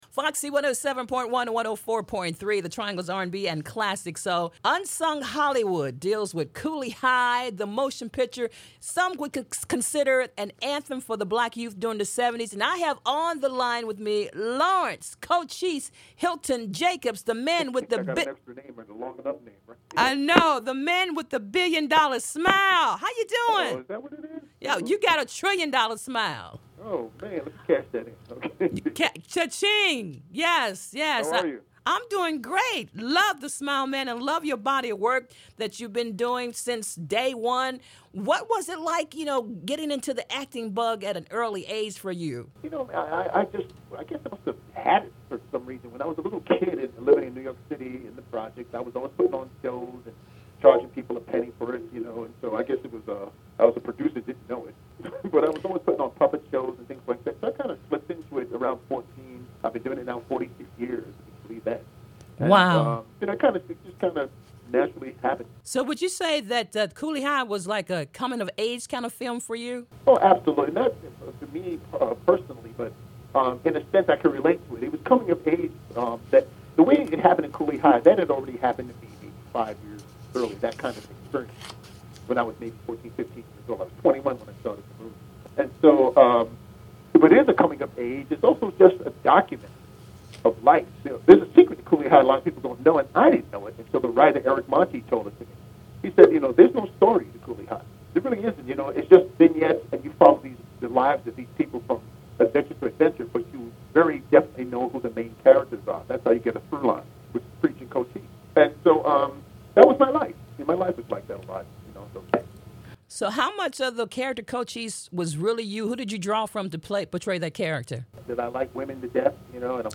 Unsung Hollywood With Cooley High Actor Lawrence Hilton-Jacobs [Interview]
Actor Lawrence Hilton-Jacobs talks about working on the set and the friendships garnered through the years.
lawrence-hilton-jacob-interview.mp3